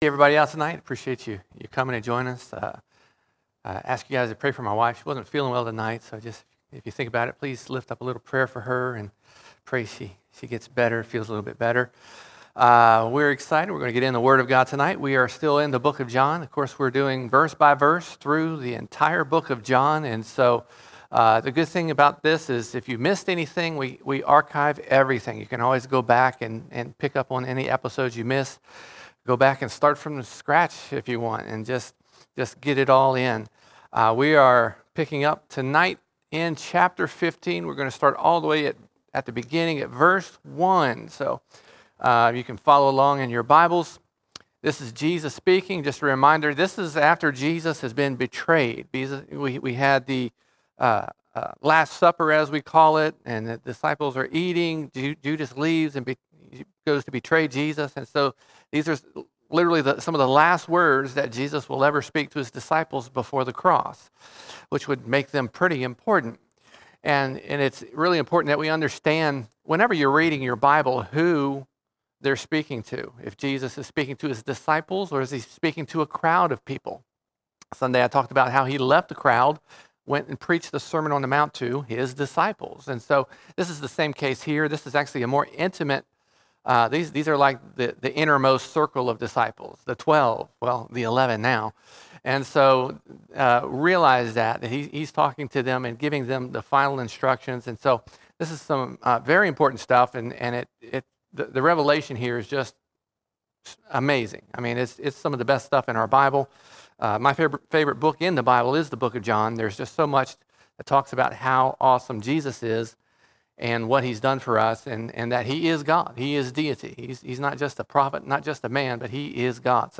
23 March 2023 Series: John All Sermons John 15:1 to 15:20 John 15:1 to 15:20 God wants us to produce fruit.